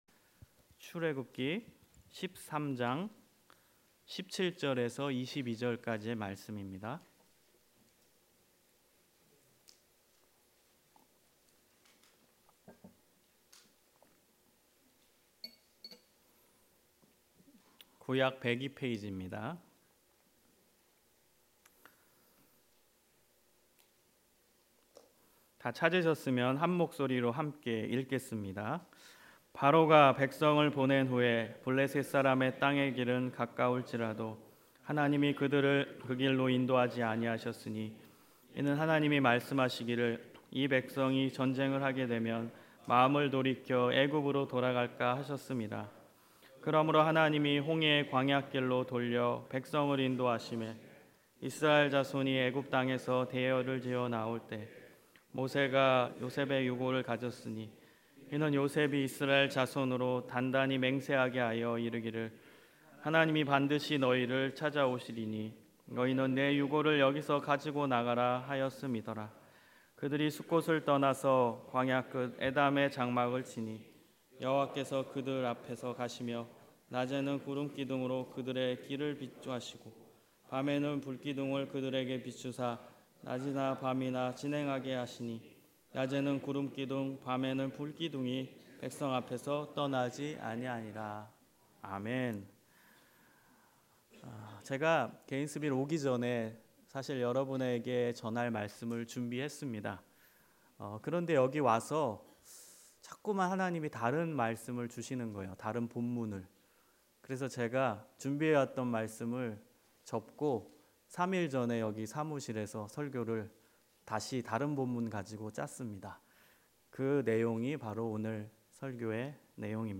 관련 Tagged with 주일예배 Audio (MP3) 66 MB 이전 야고보서 (12) - 찬송의 말과 저주의 말 다음 하나님을 언제나 신뢰하는 비결 0 댓글 댓글 추가 취소 댓글을 달기 위해서는 로그인 해야합니다.